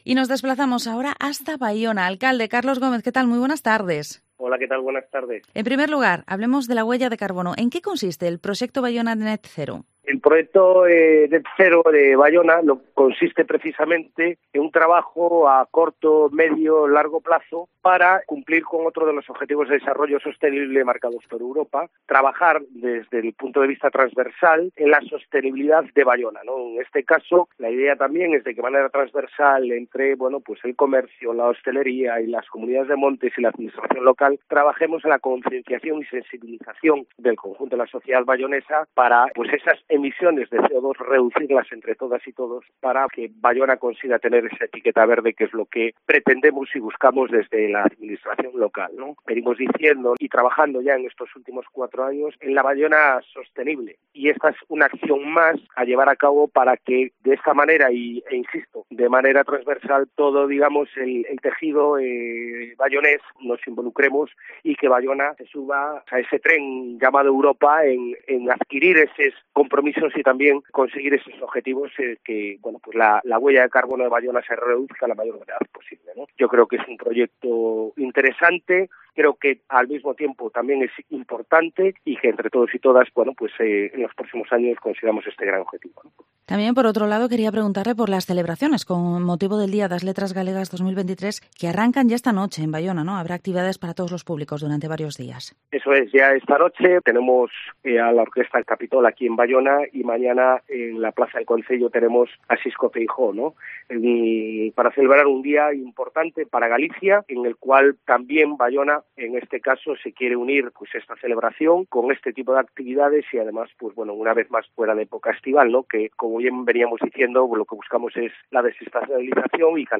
Entrevista al Alcalde de Baiona, Carlos Gómez